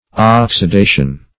Oxidation \Ox`i*da"tion\, n. [Cf. F. oxidation.]